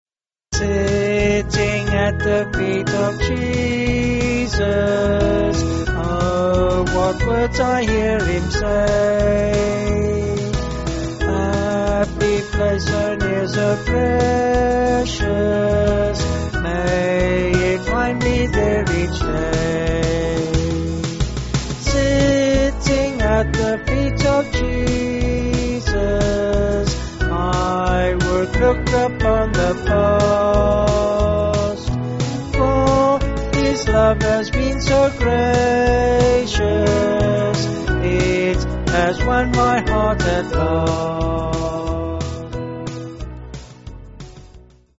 Vocals and Band